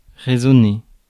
Ääntäminen
Synonyymit sound Ääntäminen US Haettu sana löytyi näillä lähdekielillä: englanti Käännös Ääninäyte Verbit 1. résonner France Määritelmät Verbit To vibrate or sound , especially in response to another vibration.